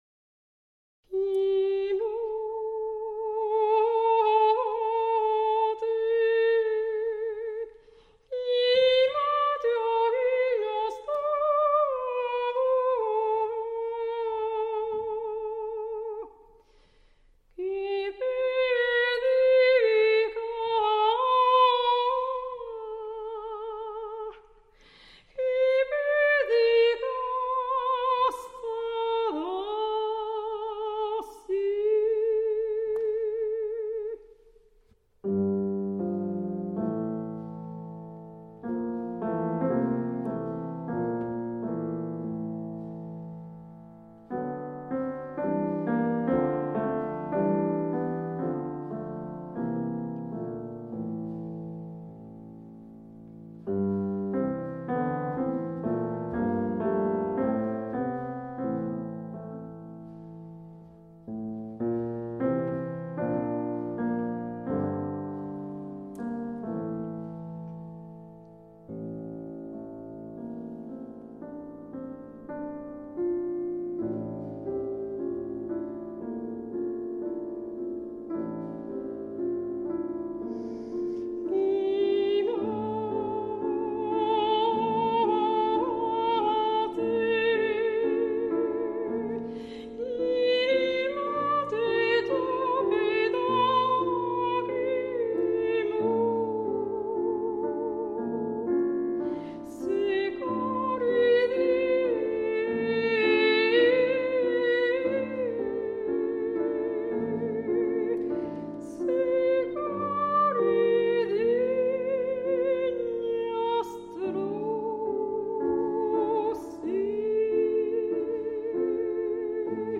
recorded in Japan
soprano
piano